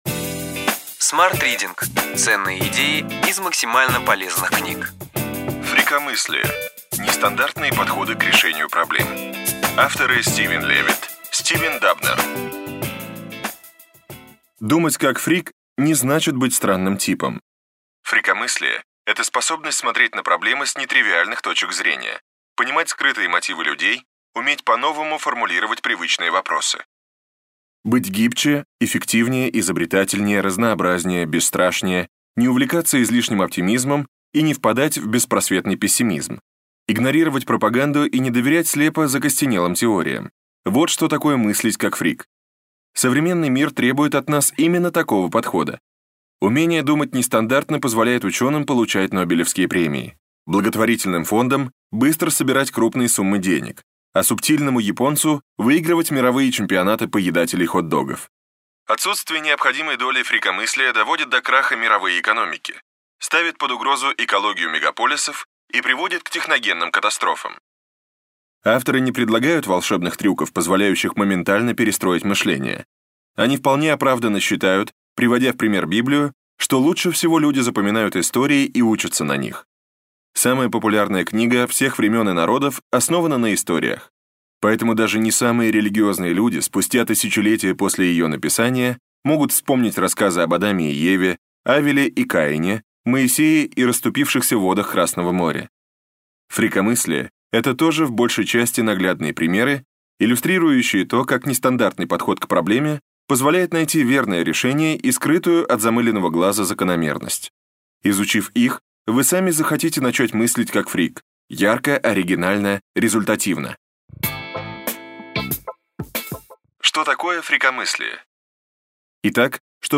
Аудиокнига Ключевые идеи книги: Фрикомыслие. Нестандартные подходы к решению проблем.